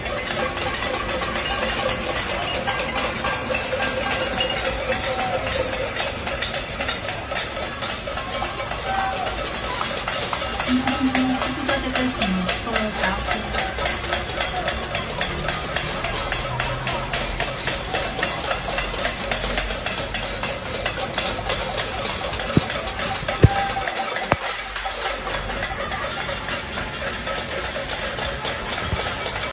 Menschen in China protestieren gegen den Lockdown, indem sie auf Töpfe und Pfannen schlagen, in der Hoffnung, dass sie freigelassen werden.